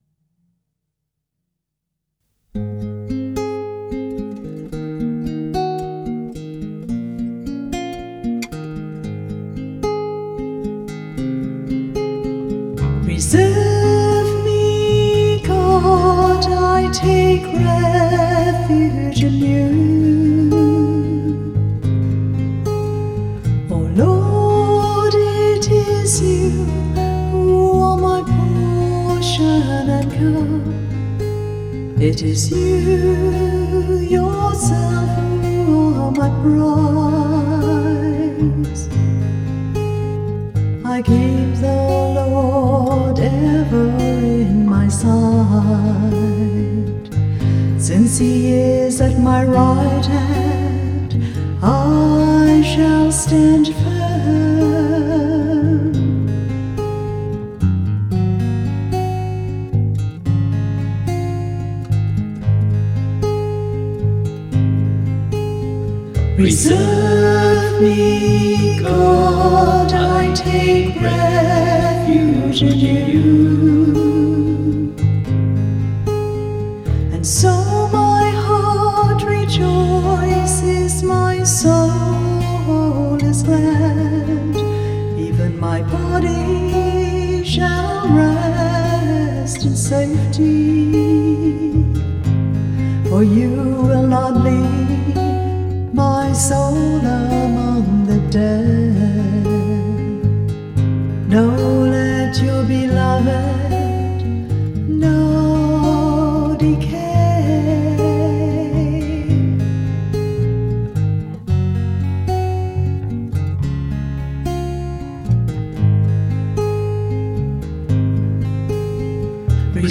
Music by the Choir of Our Lady of the Rosary RC Church, Verdun, St. John, Barbados.